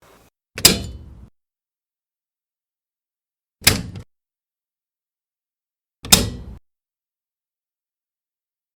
Multiple Breaker THrows
SFX
yt_mEahSUPFNiA_multiple_breaker_throws.mp3